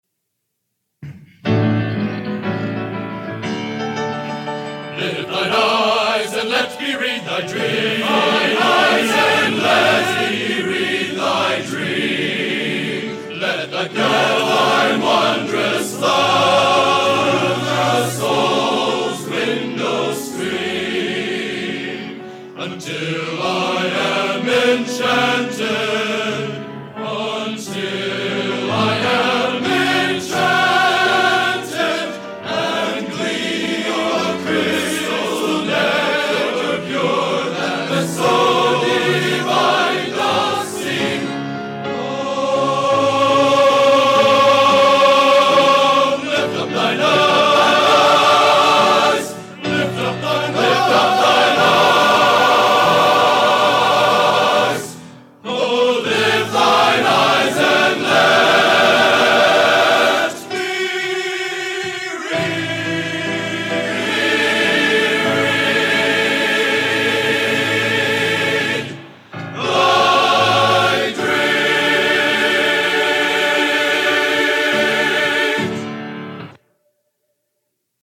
Genre: Collegiate | Type: End of Season